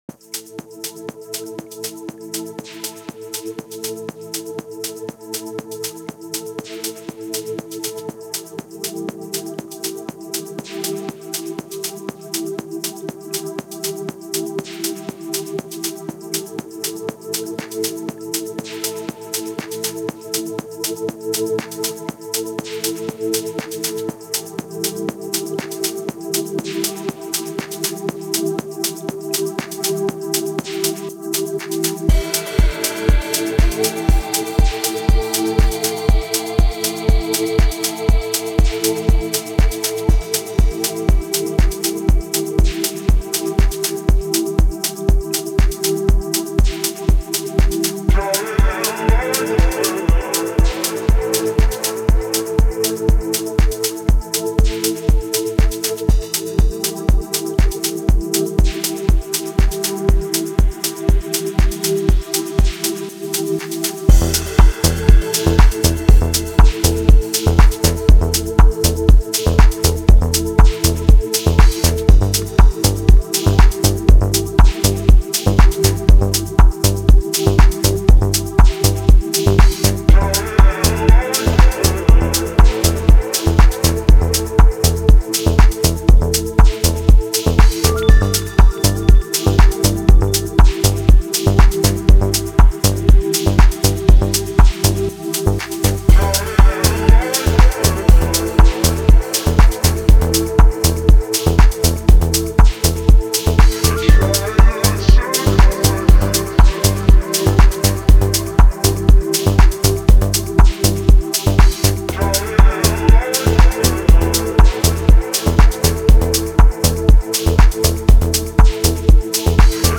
это трек в жанре поп с элементами электронной музыки